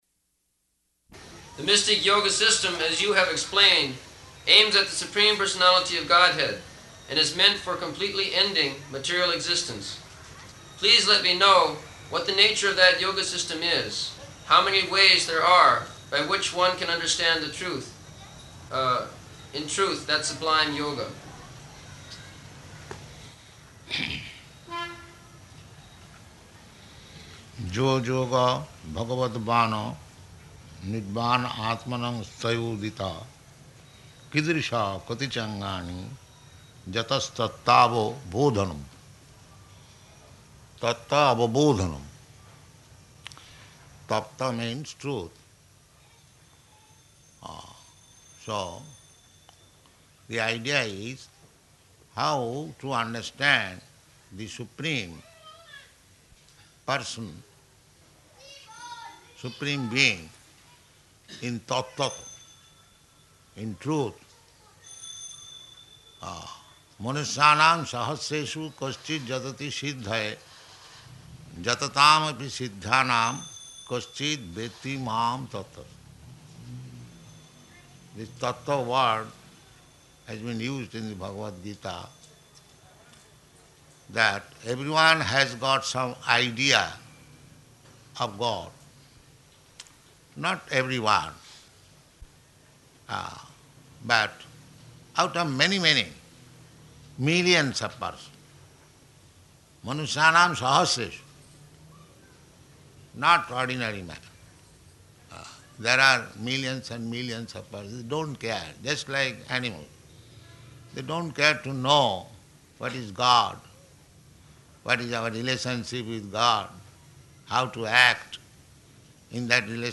Location: Bombay
[child making noise] [aside:] This child...